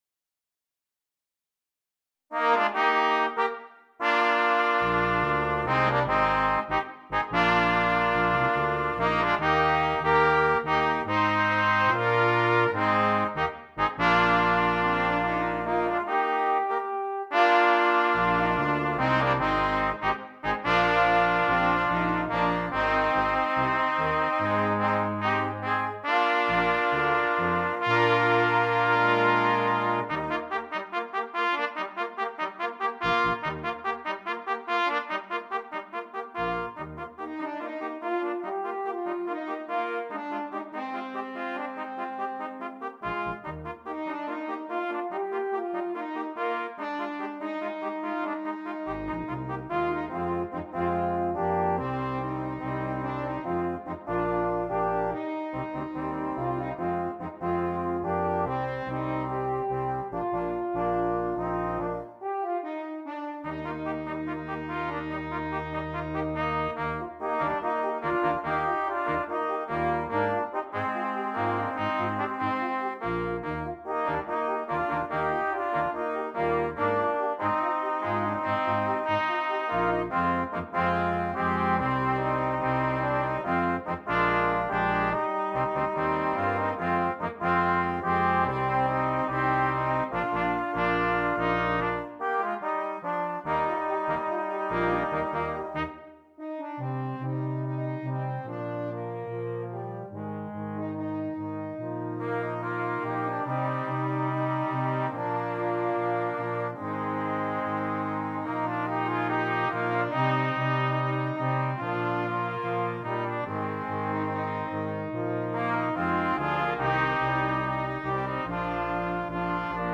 Gattung: Für Blechbläserquintett
Besetzung: Ensemblemusik für 5 Blechbläser
ist ein fröhliches, beschwingtes Stück